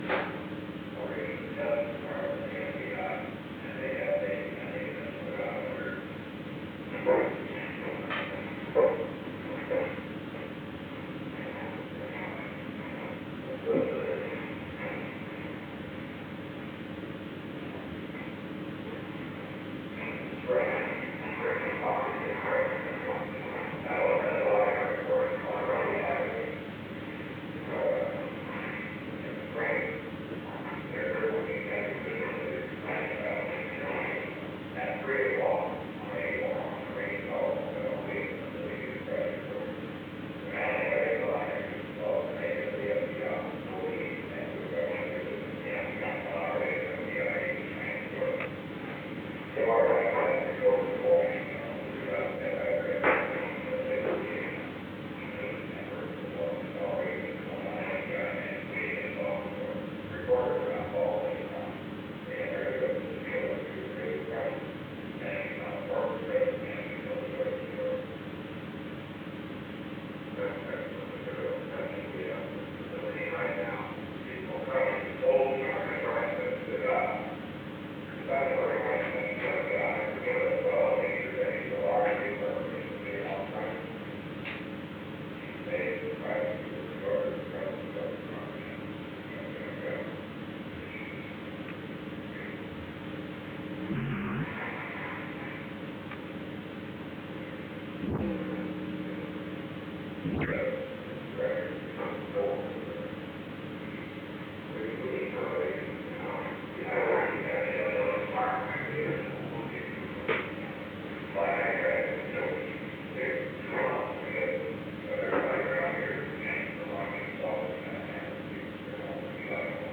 Conversation No. 442-11 Date: June 4, 1973 Time: Unknown between 12:35 and 12:51 pm Location: Executive Office Building The President played a portion of a recording of a meeting with John W. Dean, III. [See Conversation No. 866-4] The President stopped the recording at an unknown time before 12:51 pm. An unknown person entered at an unknown time after 12:35 pm.